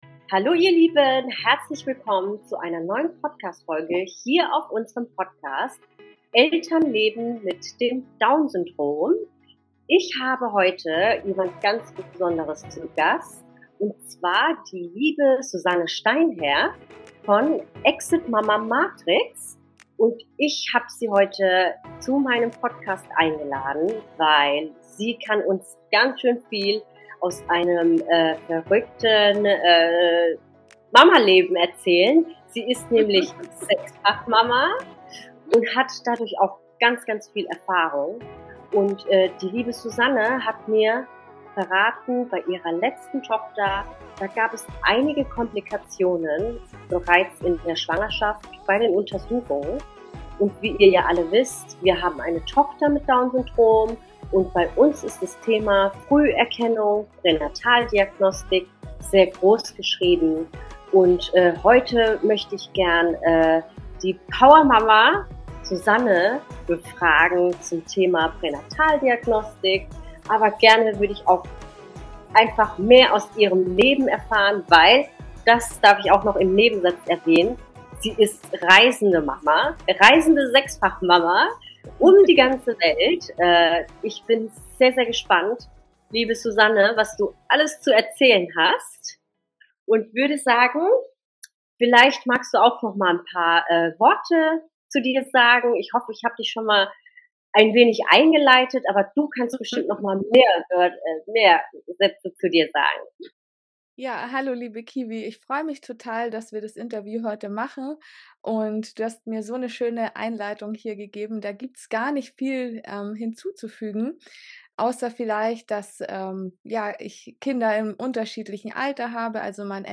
Das ist unsere Podcastfolge #8 und heute haben wir einen Gast in unserem Podcast.